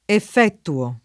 vai all'elenco alfabetico delle voci ingrandisci il carattere 100% rimpicciolisci il carattere stampa invia tramite posta elettronica codividi su Facebook effettuare [ effettu- # re ] v.; effettuo [ eff $ ttuo ] — pres. 1a pl. effettuiamo [ effettu L# mo ]